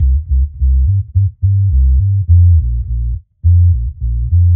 Index of /musicradar/dub-designer-samples/105bpm/Bass
DD_JBass_105_C.wav